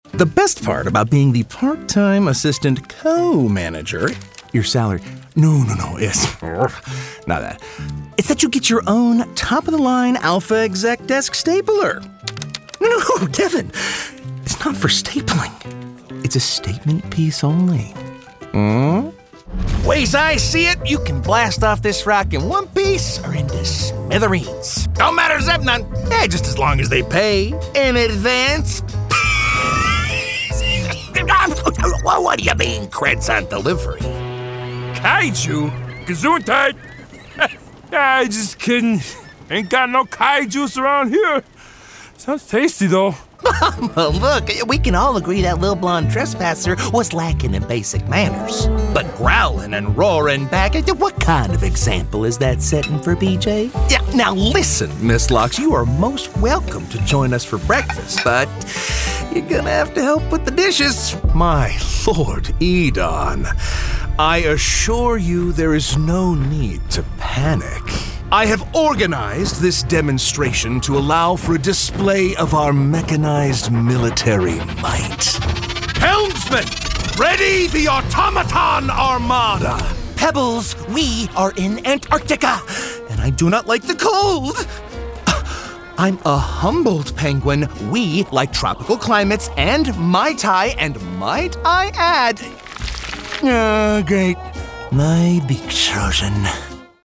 ANIMATION VOICEOVER DEMOS
A world-class, commercial grade studio in North Hollywood, CA is where all my recording takes place.